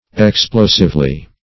Explosively \Ex*plo"sive*ly\, adv.